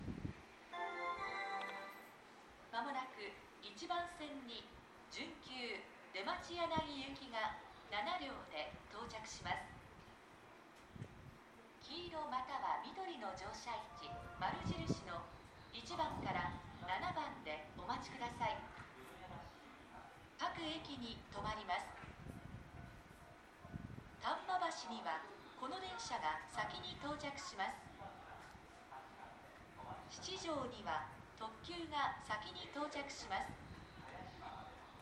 スピーカーが設置されており音量は大きめです。
ただ列車走行音と被りやすいので綺麗に録音す るのは少し難易度が高いです。
１番線京阪本線
丹波橋、祇園四条、出町柳方面   発車メロディー
接近放送  特急　出町柳行き発車メロディーです。